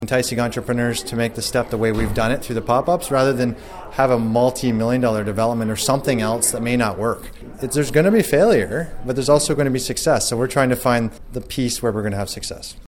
Committee Chair Councillor Ryan Williams tells  Quinte News it’s a pilot project to activate the waterfront.